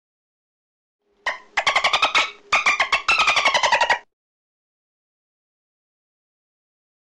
Звуки дельфинов
Слушайте онлайн или скачивайте треки в высоком качестве: игривые щелчки, мелодичные свисты и ультразвуковые волны, создающие атмосферу океанского спокойствия.
Вот так звучит дельфин